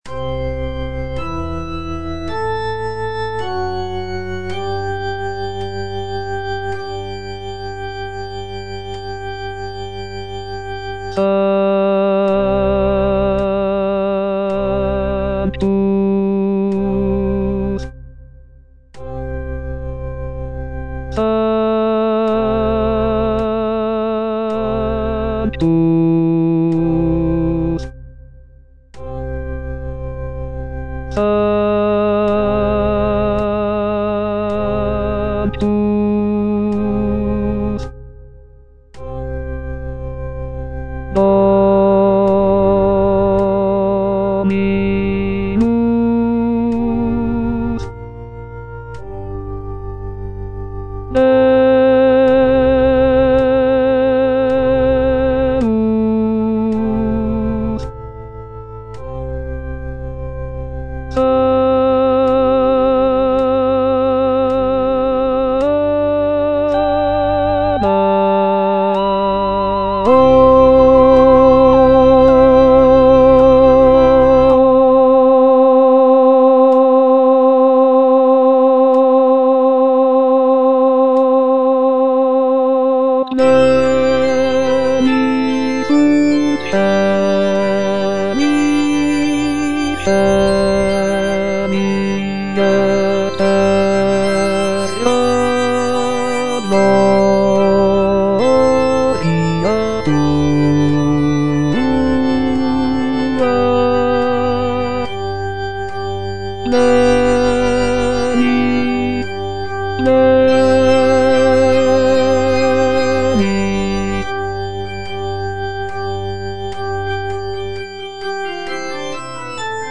C.M. VON WEBER - MISSA SANCTA NO.1 Sanctus (bass I) (Voice with metronome) Ads stop: auto-stop